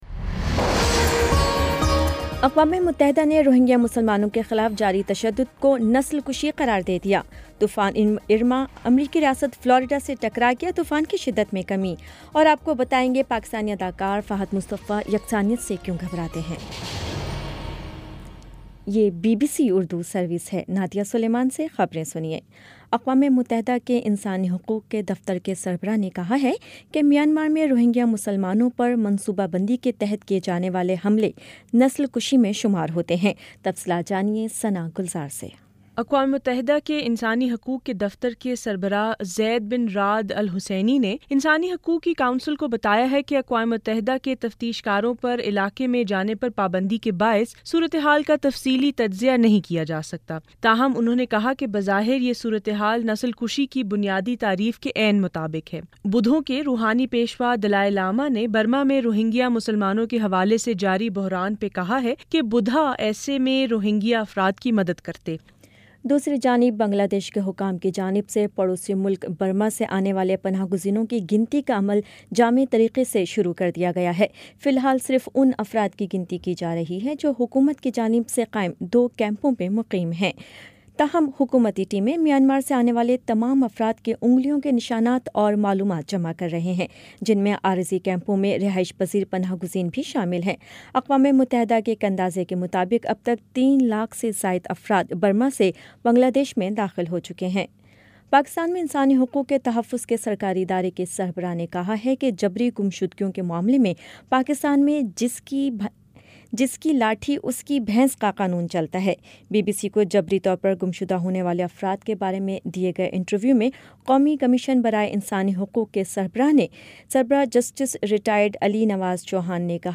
ستمبر 11 : شام پانچ بجے کا نیوز بُلیٹن